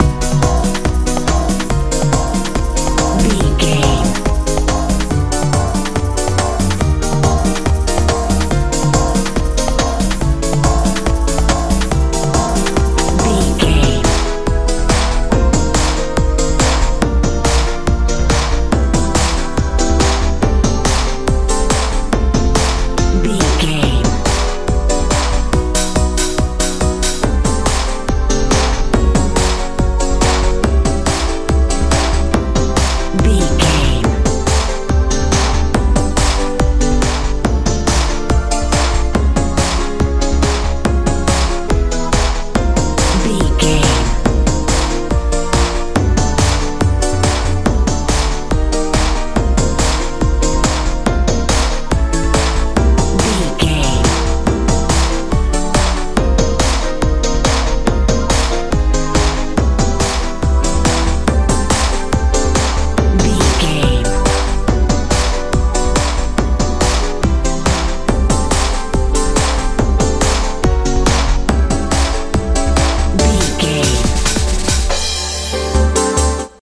dance feel
Ionian/Major
Fast
bouncy
piano
synthesiser
bass guitar
drums
80s
90s
driving
dramatic
energetic